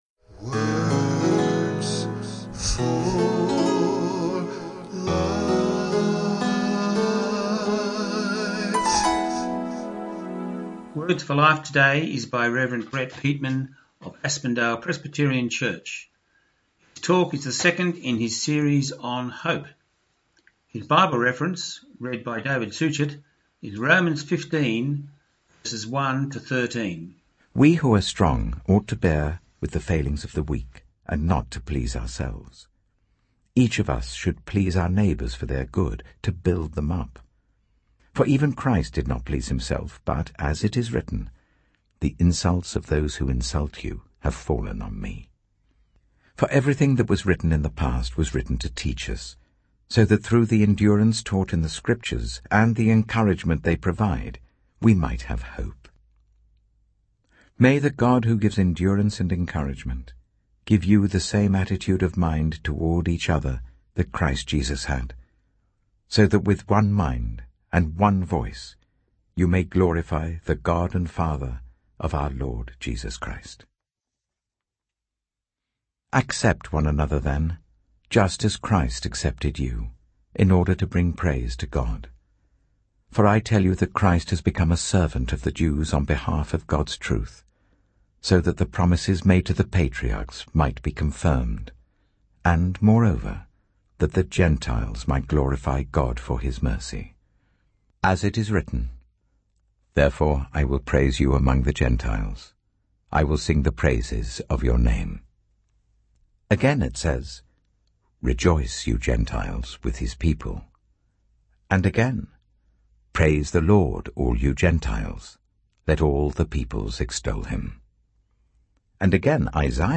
Our Words for Life sermon on Songs of Hope today